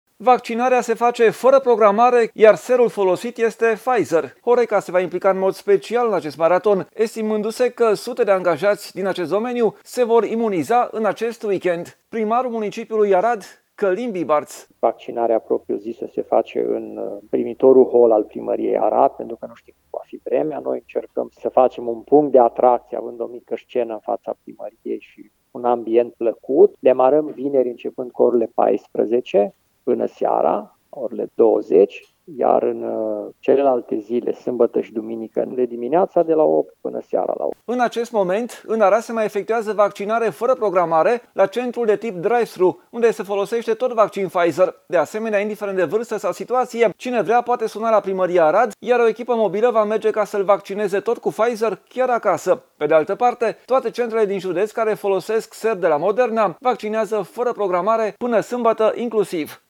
Primarul municipiului Arad, Călin Bibarț: